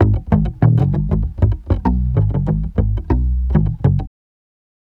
Bass Lick 34-01.wav